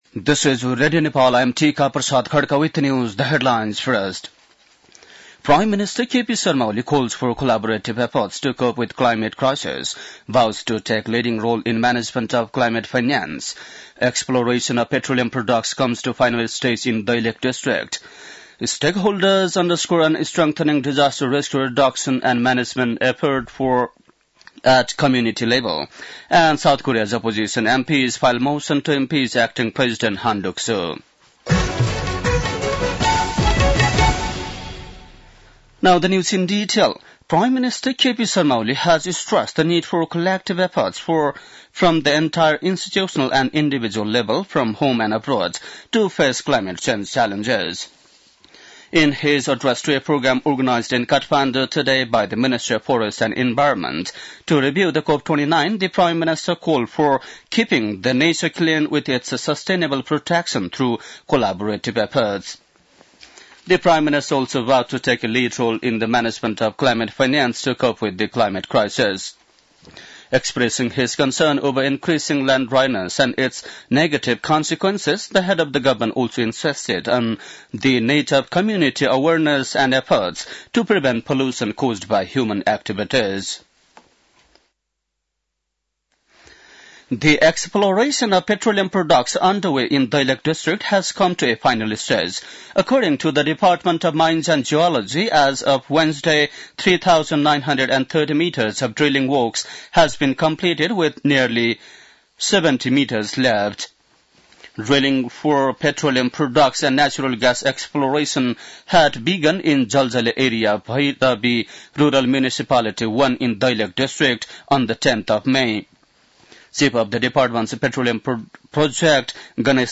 बेलुकी ८ बजेको अङ्ग्रेजी समाचार : १२ पुष , २०८१